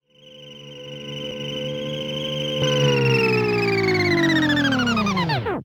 droidenters.ogg